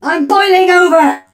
pearl_hurt_vo_05.ogg